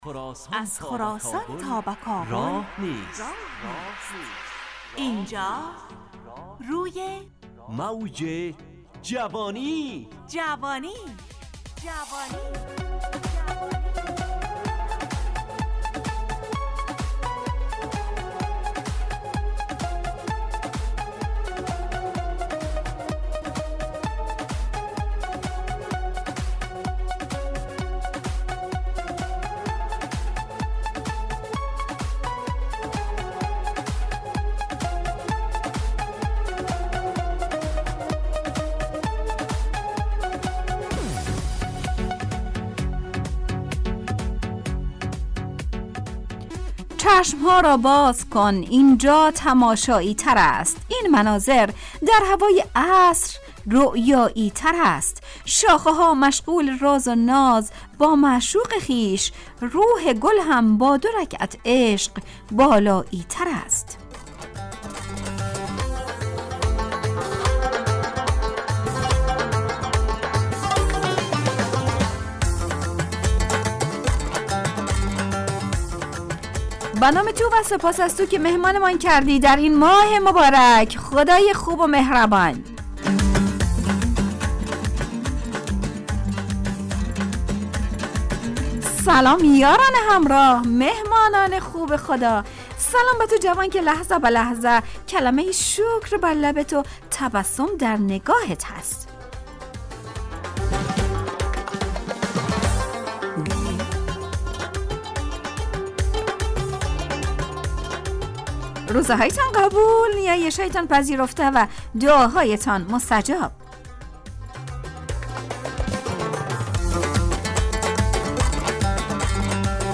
روی موج جوانی، برنامه شادو عصرانه رادیودری. از شنبه تا پنجشنبه ازساعت 4:45 الی5:55 به وقت افغانستان، طرح موضوعات روز، وآگاهی دهی برای جوانان، و.....بخشهای...
همراه با ترانه و موسیقی مدت برنامه 70 دقیقه .